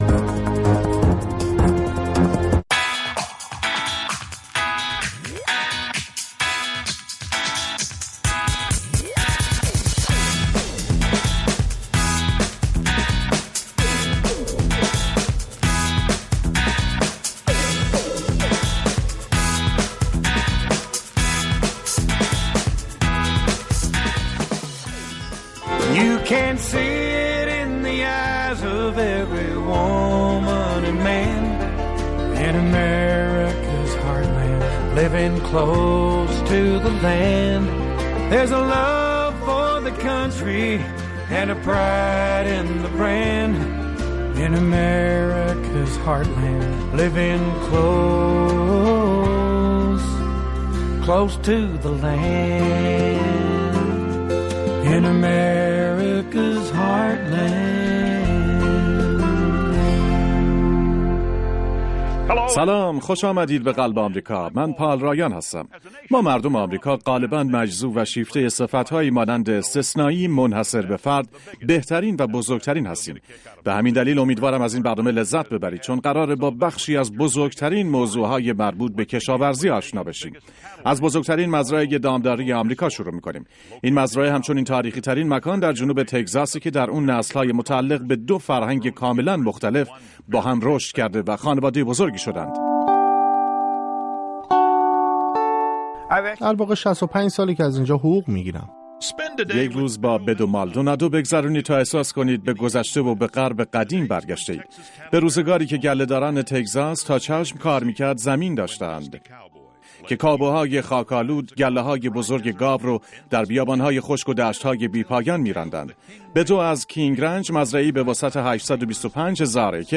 زندگی چهره های نامدار و ماندگار در اين برنامه در قالب يک مستند نيم ساعته به تصوير کشيده می شود. در اين برنامه هنرمندان، پژوهشگران، استادان دانشگاه، فعالان اجتماعی و ديگر شخصيتهای نام آشنا ما را با زندگی پر بار خود آشنا می کنند.